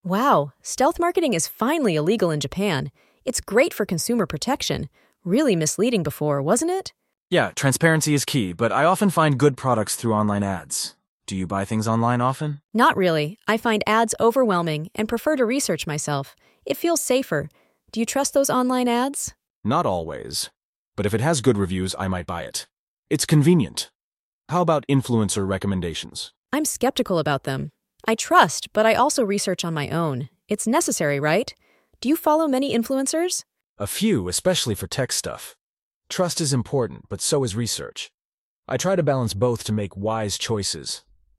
話題性の高いニュースTopic「ステマとネット広告」に関する会話テキストを元に、アメリカ英語の自然な口語を学びます。
友人どうしが時事問題について話し合っています。